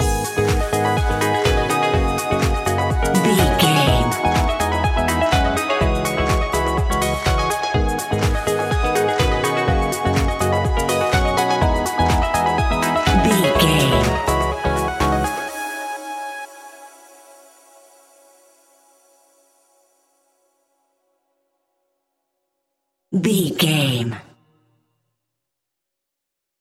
Aeolian/Minor
G#
groovy
uplifting
energetic
bouncy
synthesiser
drum machine
electro house
synth bass